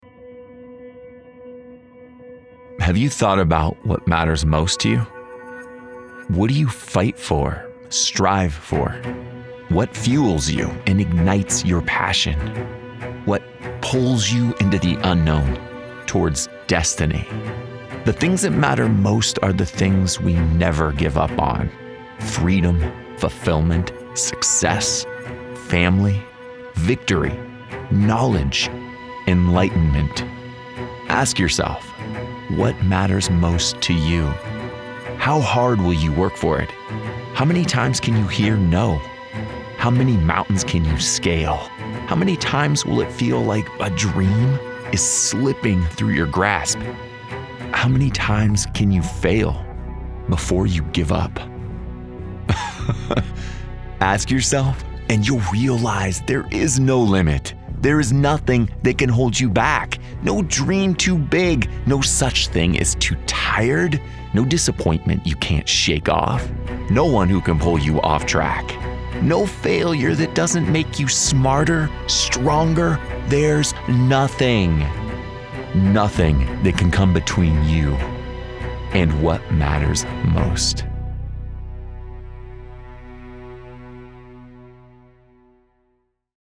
Inspirational